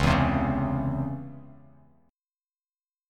C#mM7 chord